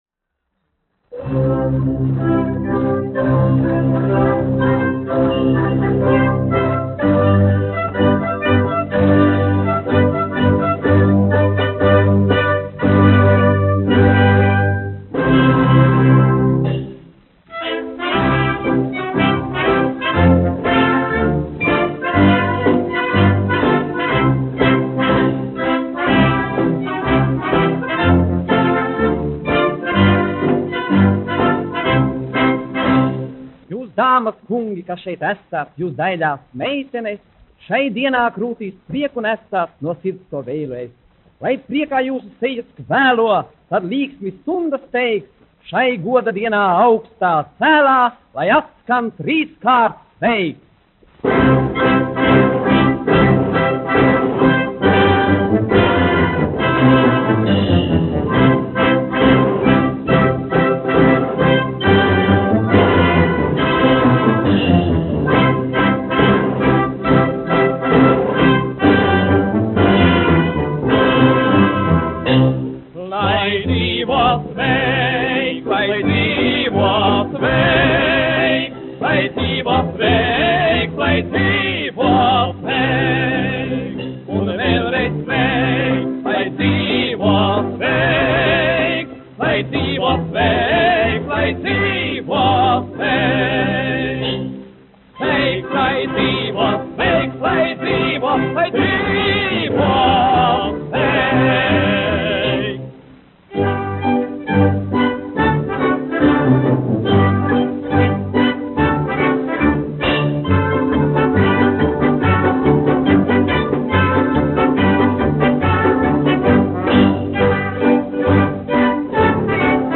1 skpl. : analogs, 78 apgr/min, mono ; 25 cm
Monologi ar mūziku (balss ar orķestri)
Populārā mūzika -- Latvija